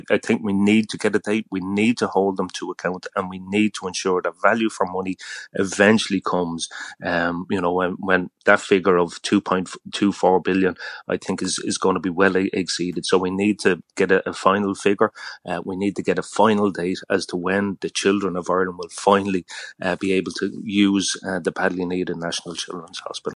The Committee Chair, Deputy John Brady, says clarity is needed: